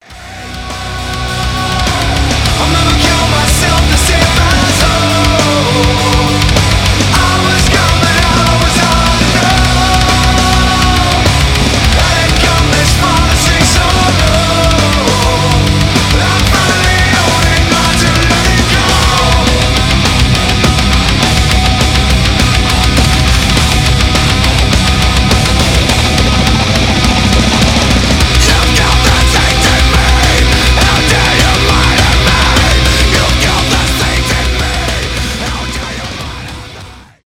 мощные , альтернатива metal
nu metal